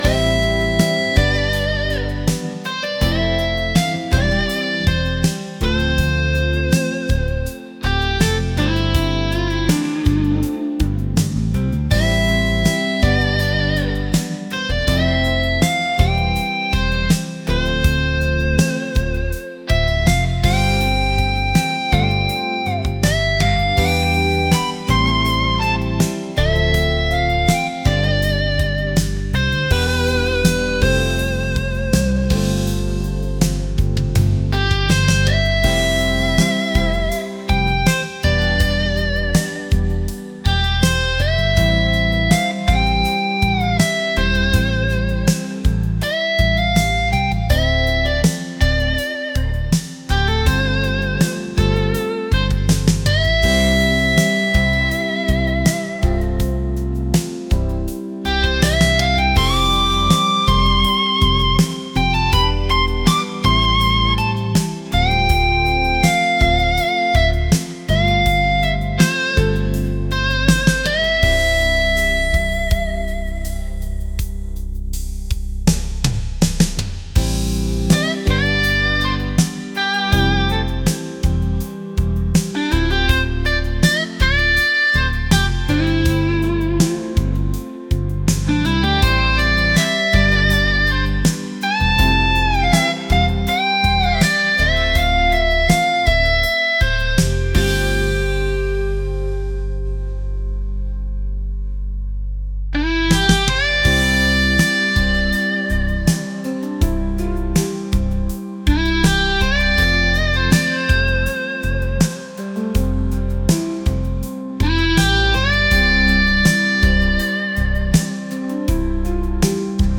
smooth | rock